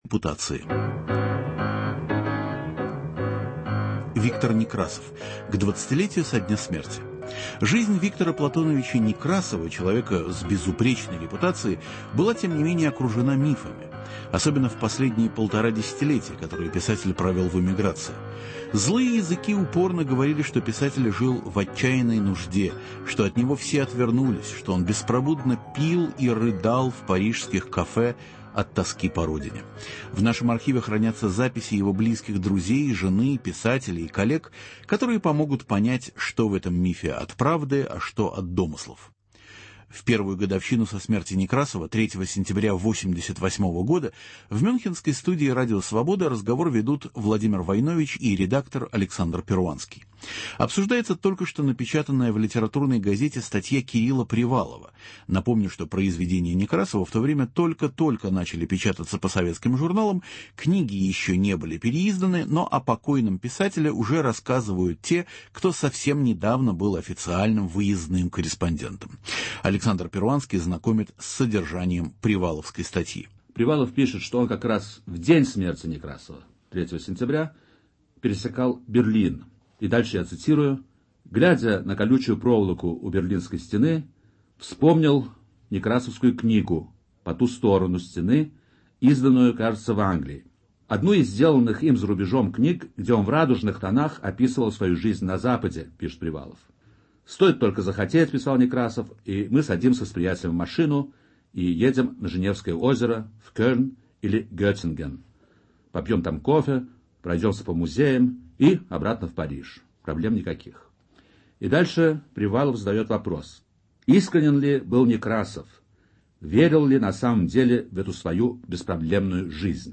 Программа Радио Свобода рассказывает о подлинном Викторе Некрасове устами его близких друзей, жены, писателей и коллег.